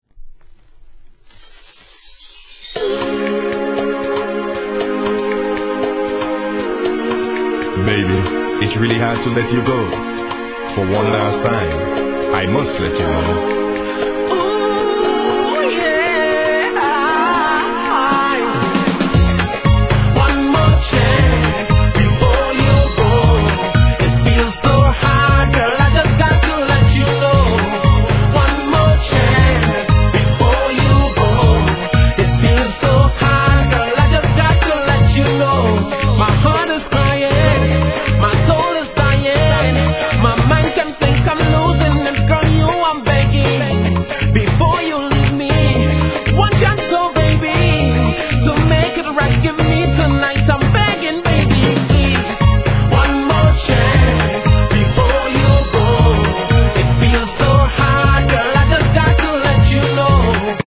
REGGAE
お別れの前にやりたい一身、美声で口説きます♪